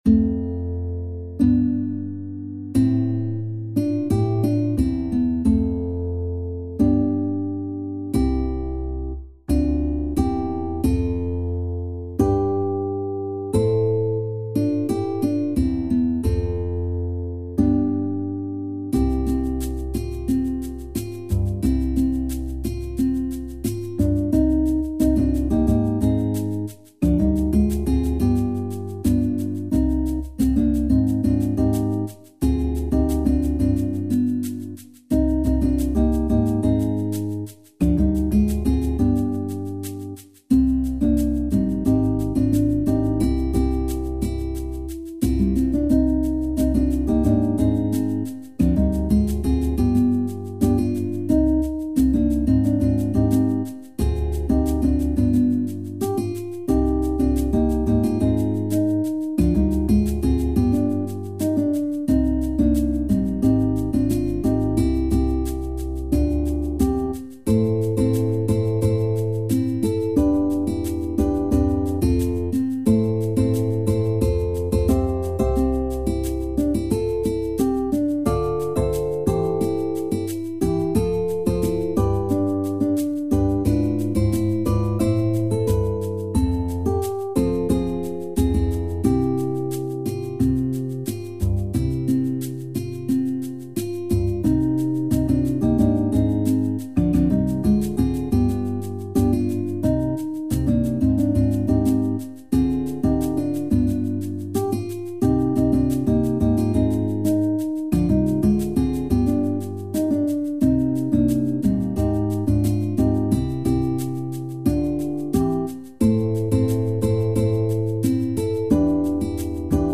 SSSAA | SSAAB